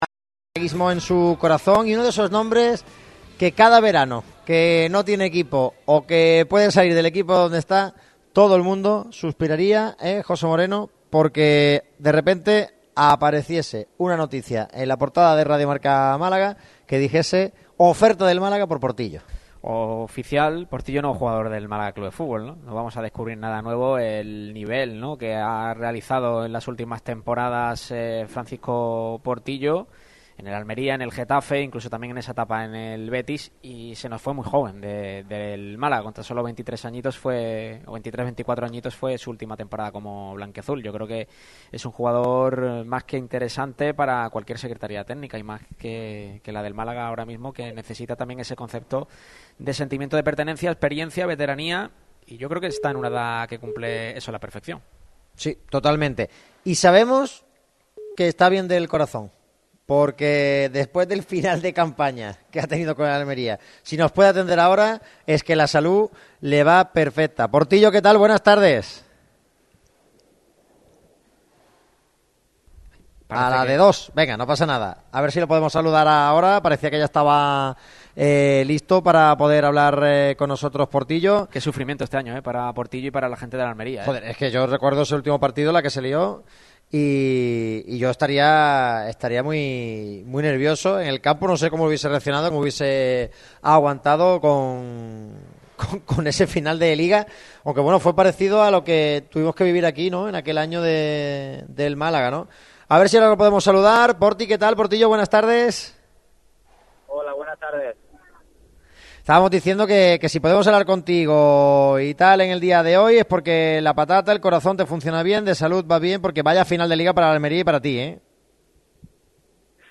El futbolista malagueño ha atendido a Radio MARCA Málaga durante el programa de hoy.